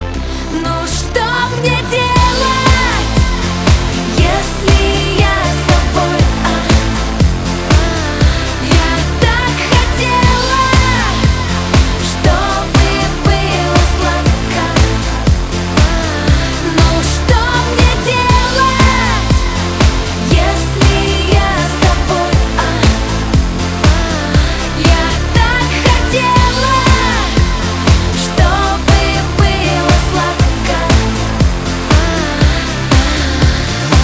поп
битовые